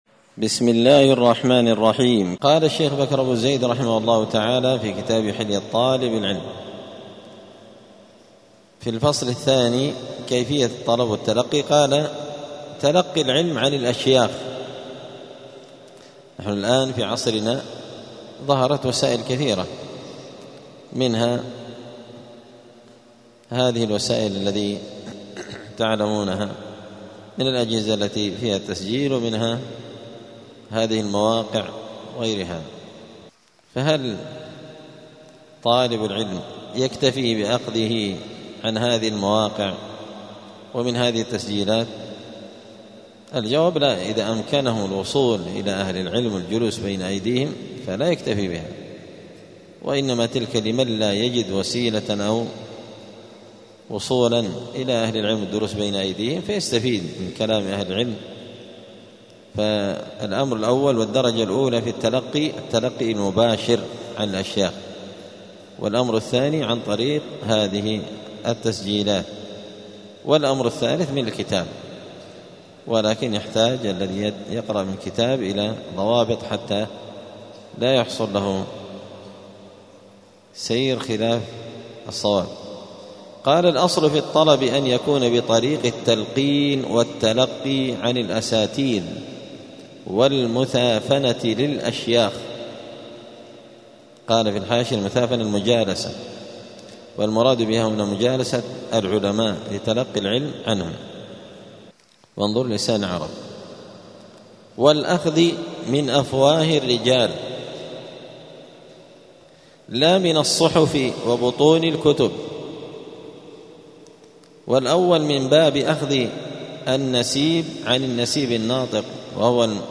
*الدرس السابع والعشرون (27) {فصل كيفية الطلب تلقي العلم عن الأشياخ}*